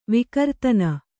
♪ vikartana